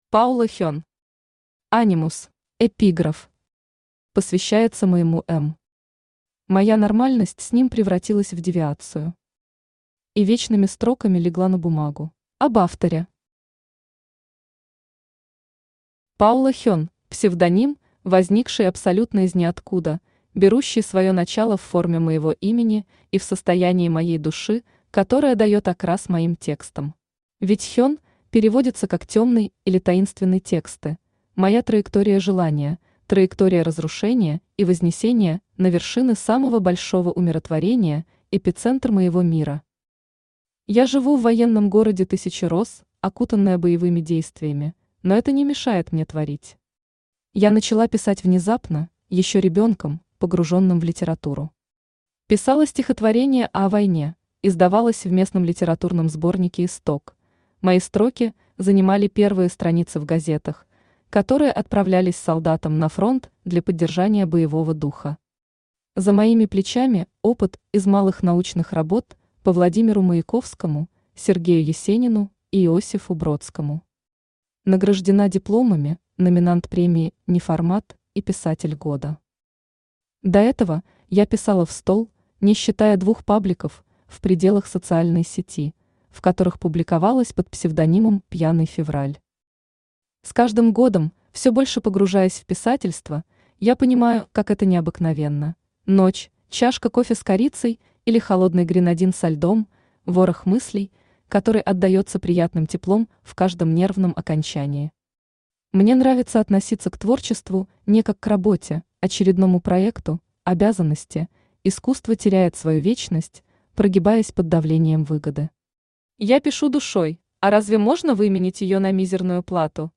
Аудиокнига Анимус | Библиотека аудиокниг